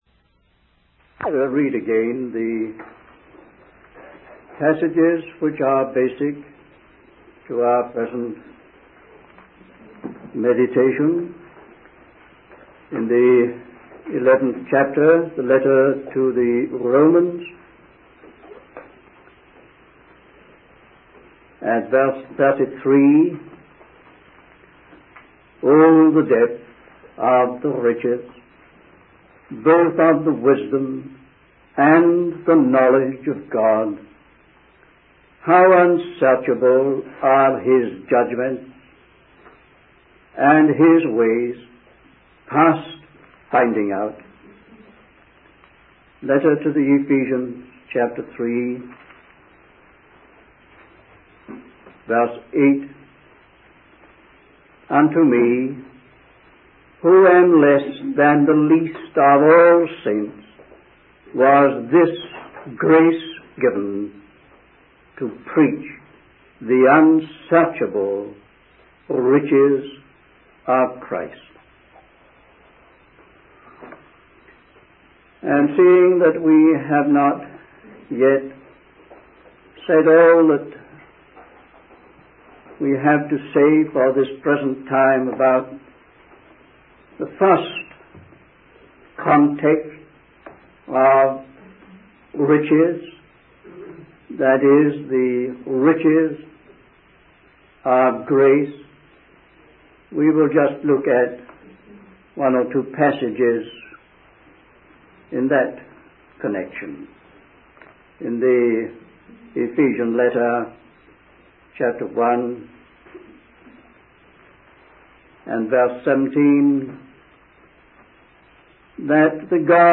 In this sermon, the speaker focuses on the concept of grace and its significance in the lives of believers. He highlights five aspects of God's grace, which he refers to as the 'exceeding riches of his grace.'